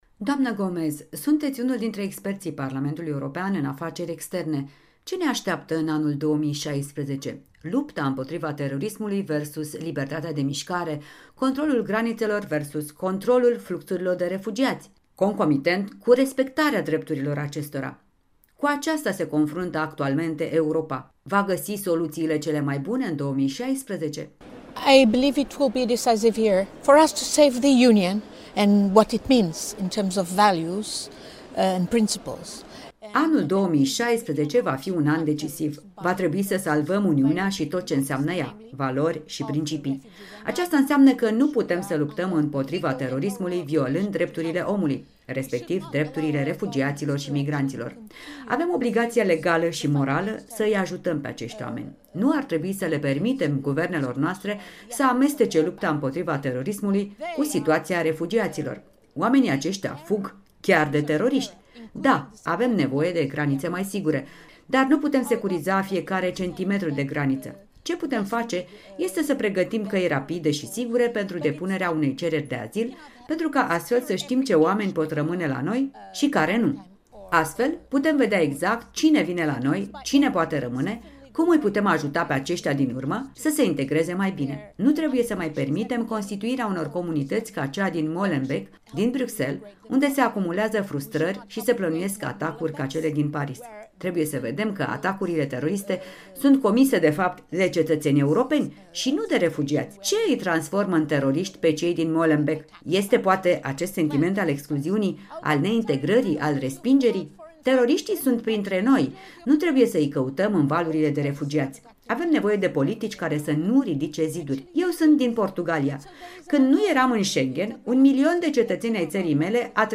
Un interviu la Strasbourg cu europarlamentara Ana Gomes